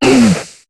Cri de Chartor dans Pokémon HOME.